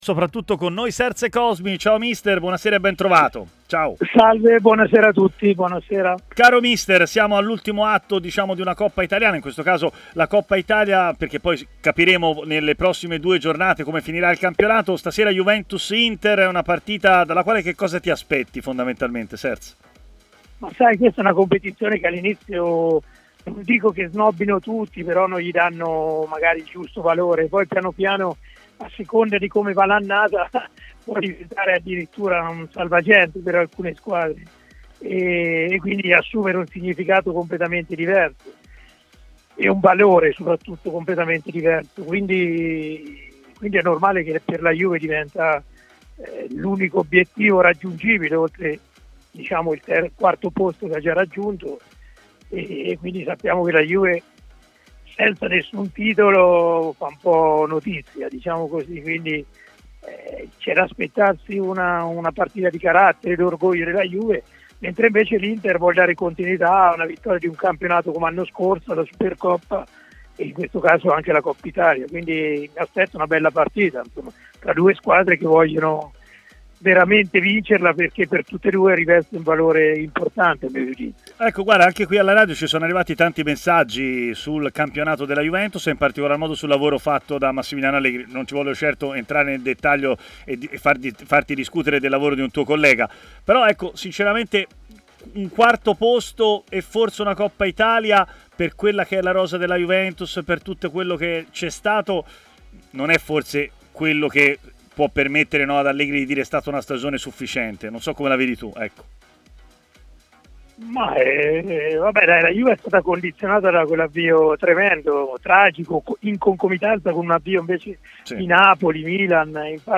L'allenatore Serse Cosmi è intervenuto in diretta su TMW Radio, durante la trasmissione Stadio Aperto